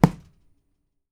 PUNCH E   -S.WAV